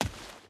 Dirt Run 5.wav